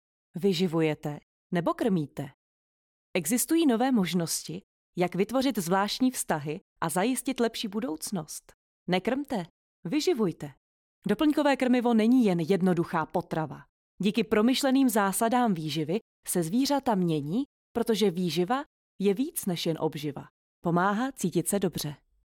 Umím: Voiceover
Výživa VO reklama.mp3